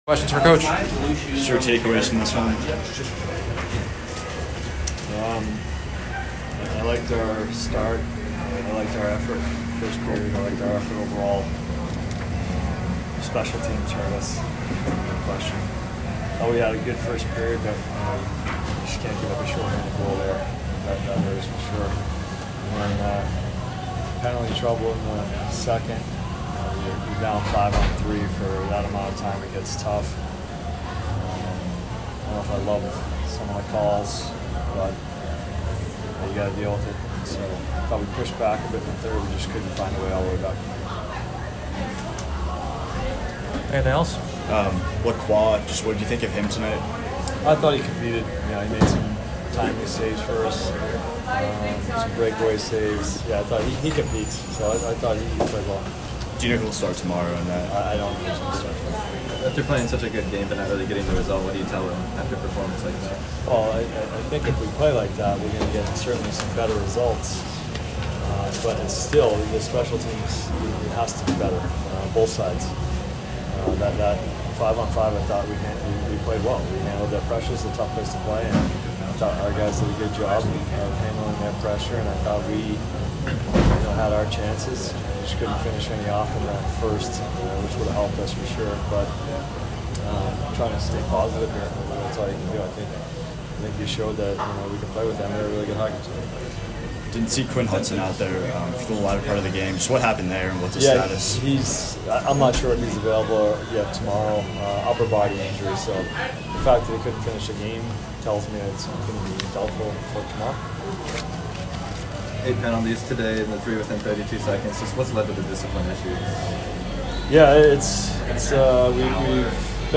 Men's Hockey / Maine Postgame Interview